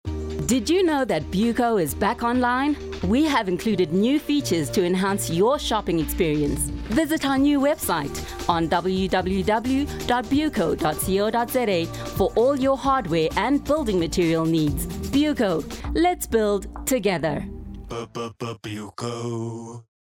animation, articulate, bright, commercial, confident, conversational, friendly, High Energy, Trendy